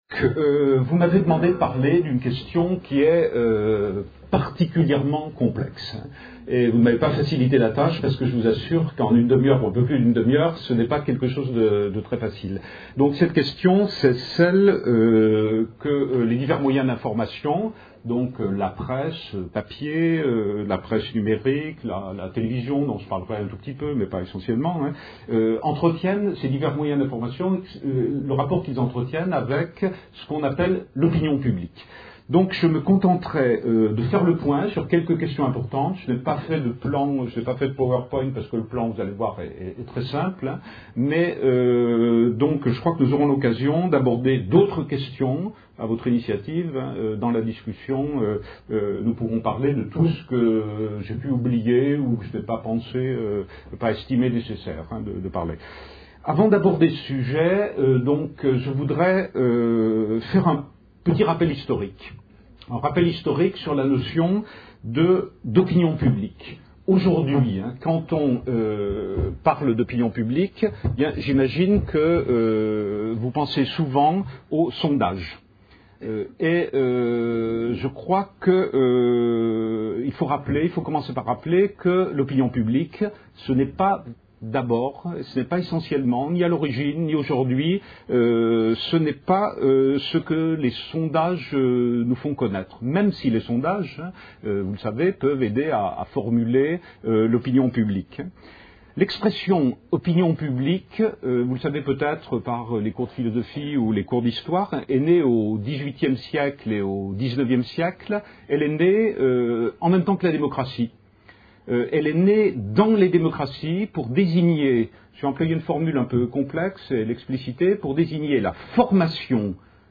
Une conférence de l'UTLS au Lycée Presse écrite